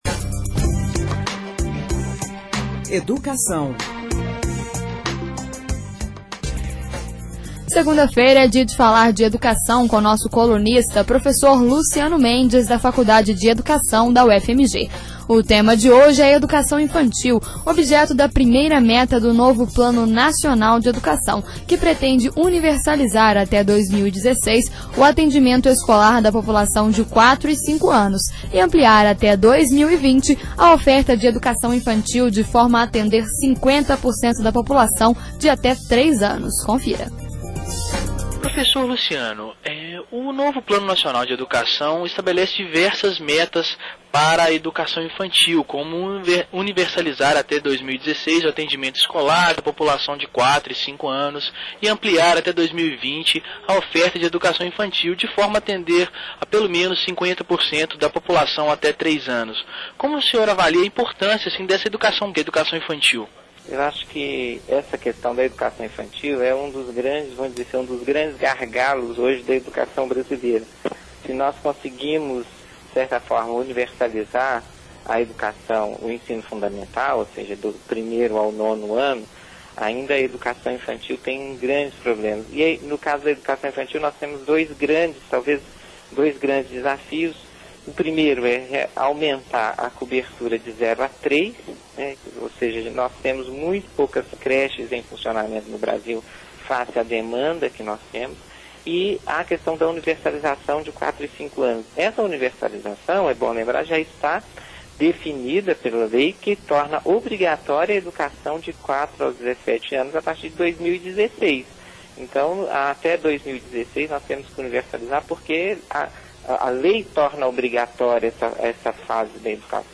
A tema de hoje é a educação infantil, objeto da primeira meta do novo Plano Nacional de Educação, que pretende Universalizar, até 2016, o atendimento escolar da população de 4 e 5 anos, e ampliar, até 2020, a oferta de Educação Infantil de forma a atender a 50% da população de até 3 anos. Entrevista